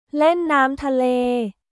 เล่นน้ำทะเล　レン・ナーム・タレー